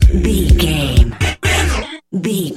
Aeolian/Minor
E♭
synthesiser
90s